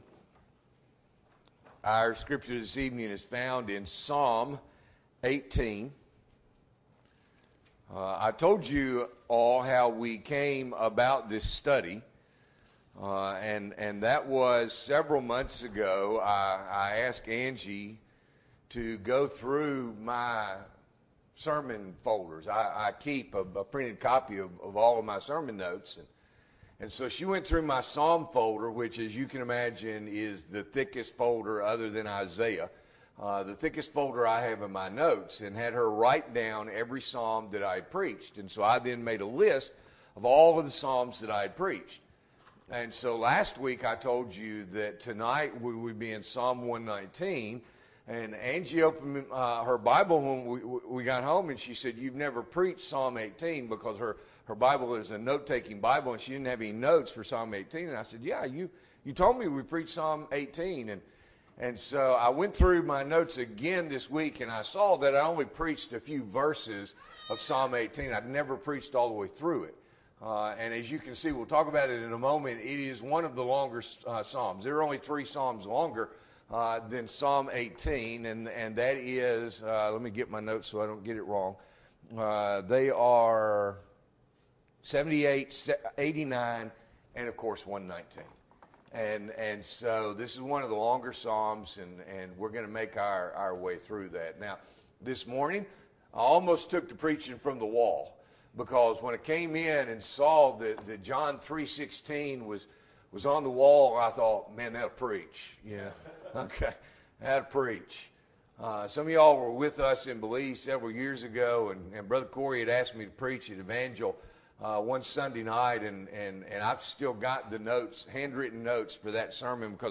March 19, 2023 – Evening Worship